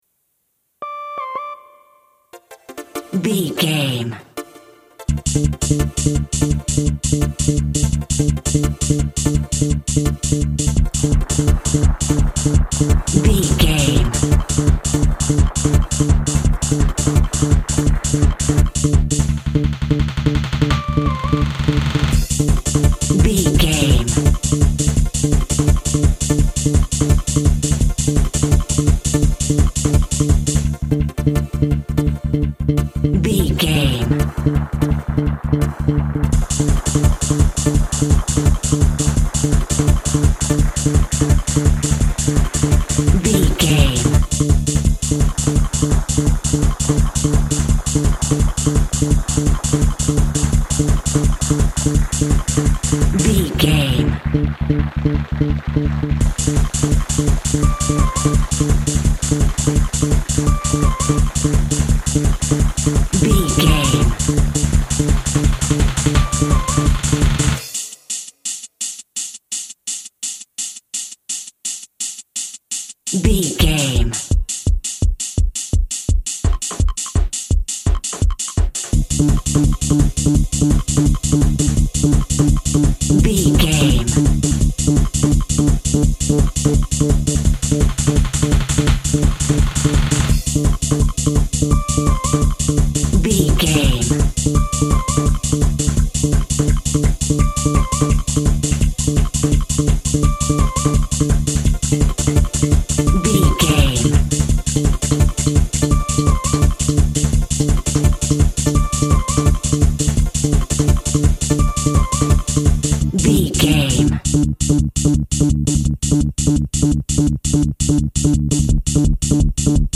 Trance Gaming Music.
Aeolian/Minor
Fast
driving
energetic
futuristic
hypnotic
frantic
electronic
synth lead
synth bass
Electronic drums
Synth pads